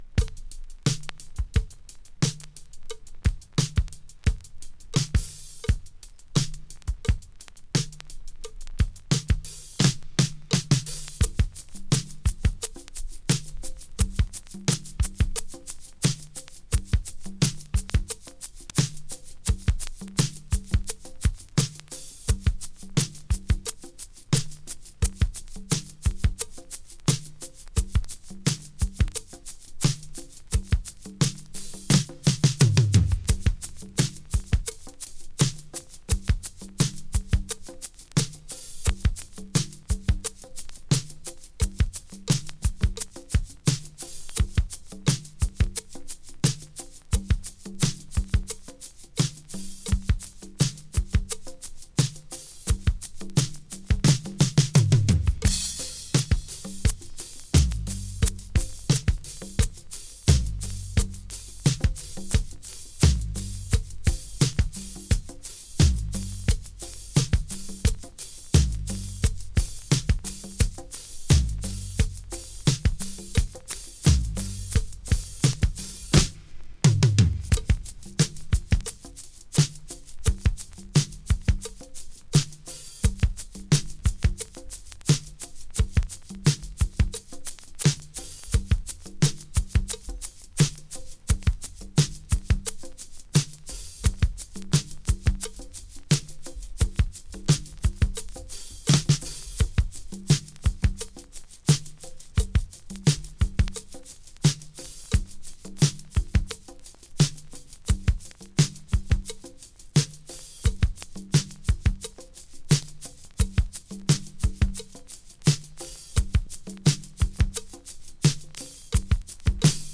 Drum tracks for practicing！
【JAZZ FUNK】
VG+ 若干のチリノイズ sleeve